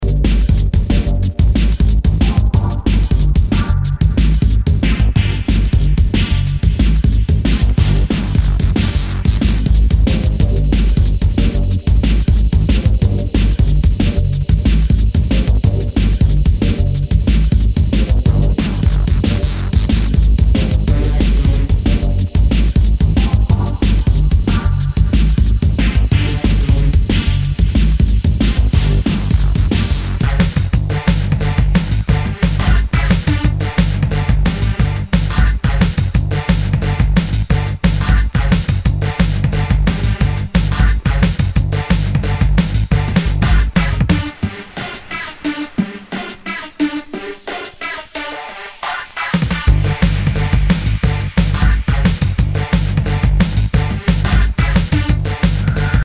Nu- Jazz/BREAK BEATS
ナイス！ドラムンベース！
全体にチリノイズが入ります